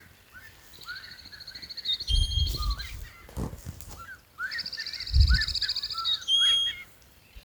Federal (Amblyramphus holosericeus)
Nombre en inglés: Scarlet-headed Blackbird
Provincia / Departamento: Entre Ríos
Condición: Silvestre
Certeza: Observada, Vocalización Grabada